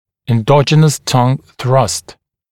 [enˈdɔʤənəs tʌŋ θrʌst][энˈдоджинэс тан сраст]эндогенное прокладывание языка